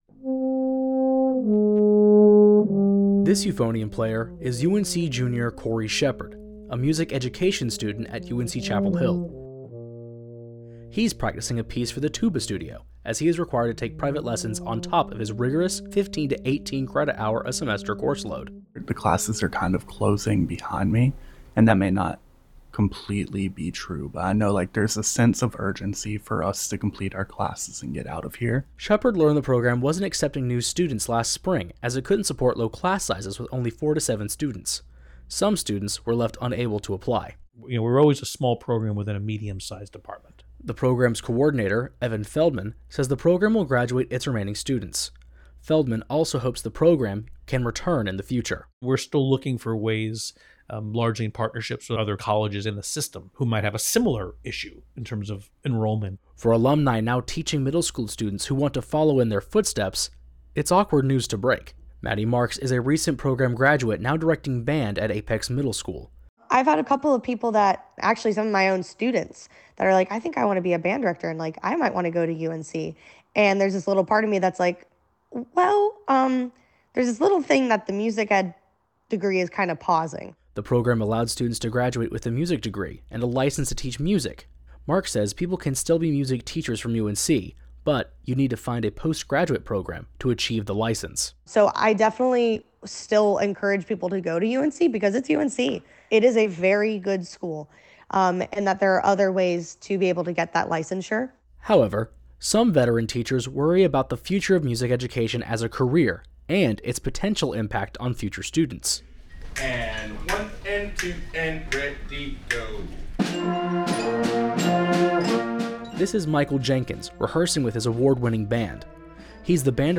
Audio report on why the music education track at UNC is on an indefinite pause.